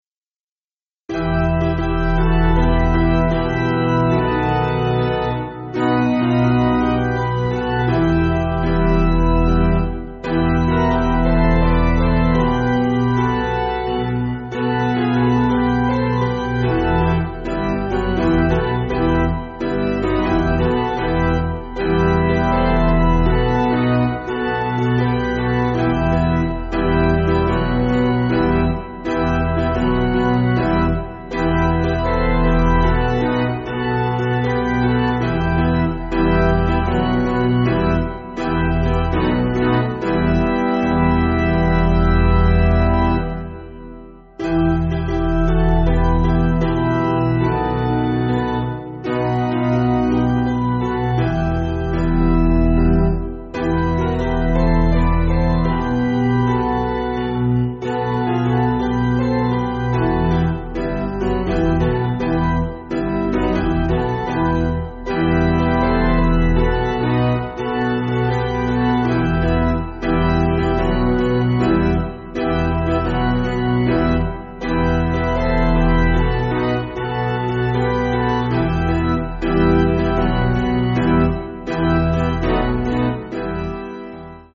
Basic Piano & Organ
(CM)   3/Em